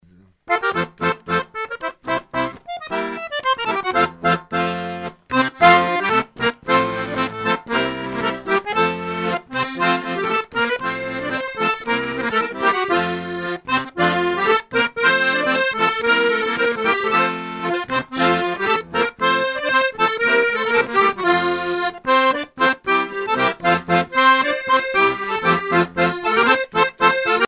danse : polka des bébés ou badoise ;
Coueff's et Chapias Groupe folklorique
répertoire du groupe Coueff's et Chapias en spectacle
Pièce musicale inédite